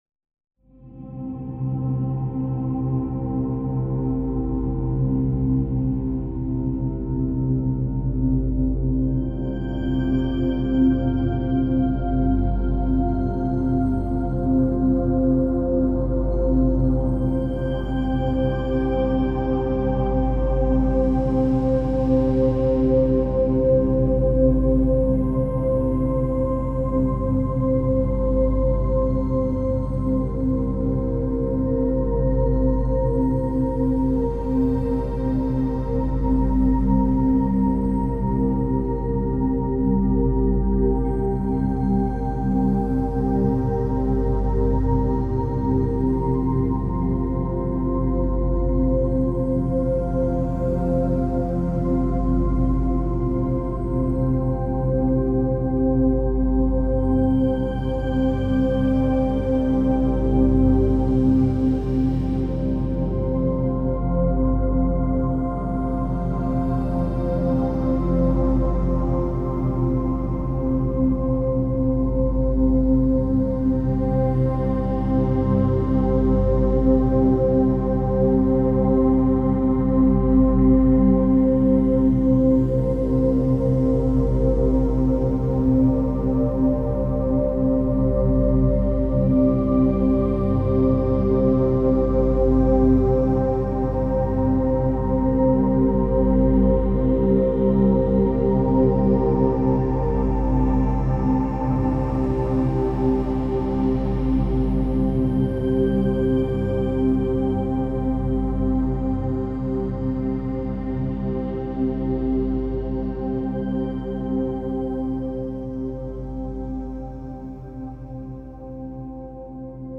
stellar music